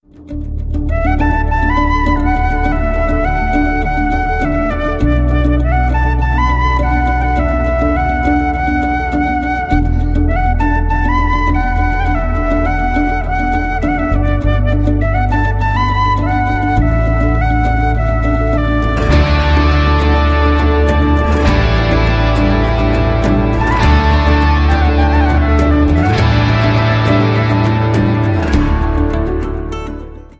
• Sachgebiet: Instrumental